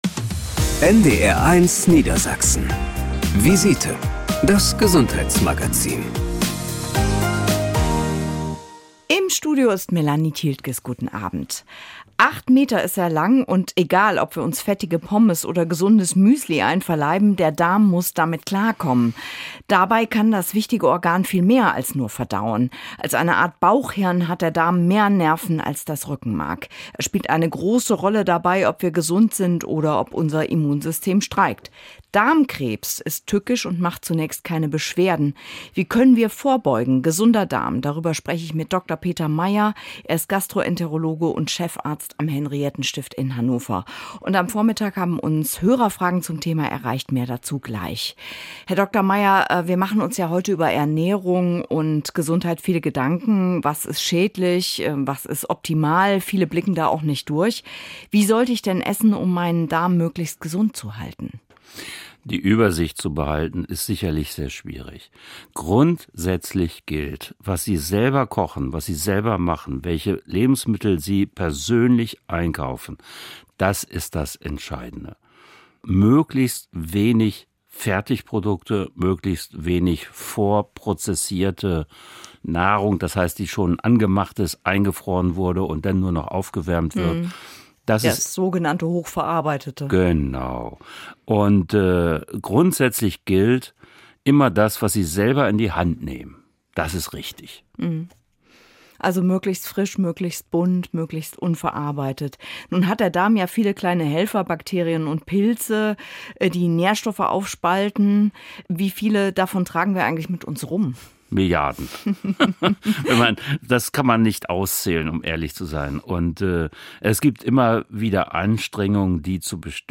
Angststörungen zählen neben den Depressionen zu den häufigsten psychischen Erkrankungen. Ein Facharzt erläutert in der Sendung, wie Angststörungen entstehen und wie sie behandelt werden können.